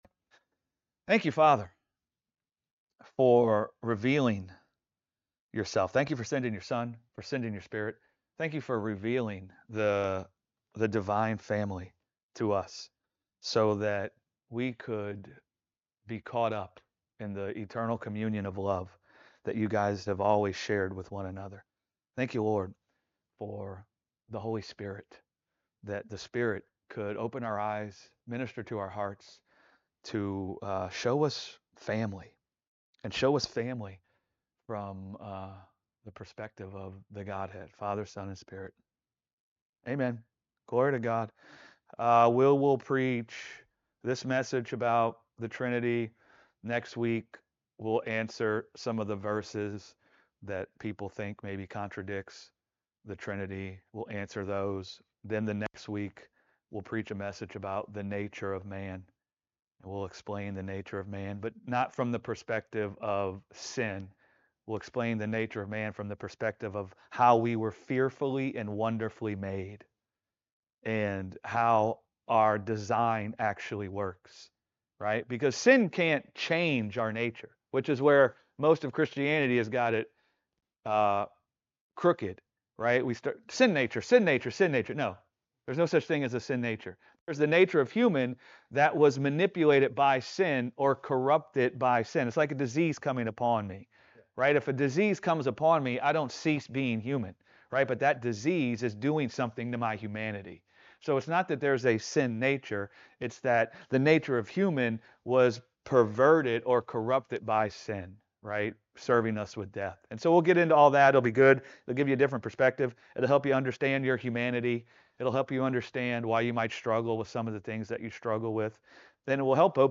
ministering at Gospel Revolution Church discussing divine simplicity, family logic, and our humanity is made whole through communion with the divine family.